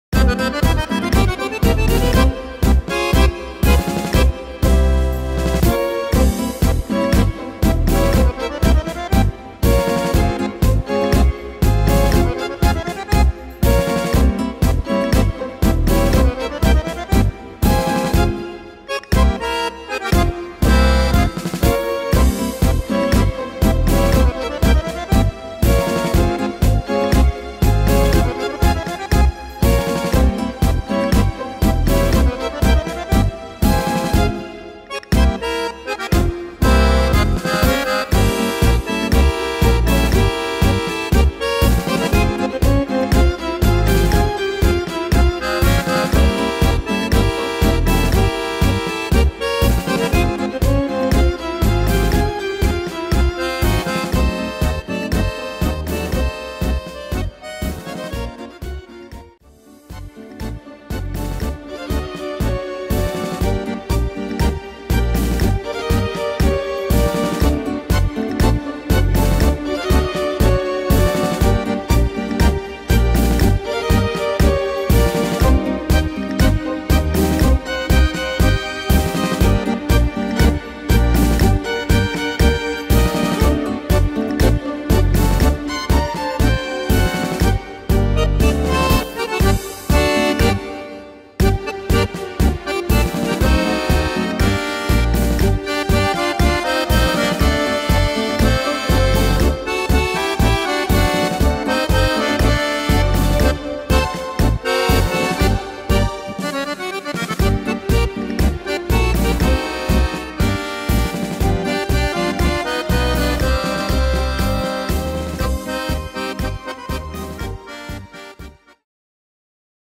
Tempo: 120 / Tonart: Ab-Dur